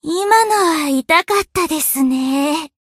贡献 ） 分类:蔚蓝档案语音 协议:Copyright 您不可以覆盖此文件。
BA_V_Hanako_Battle_Damage_3.ogg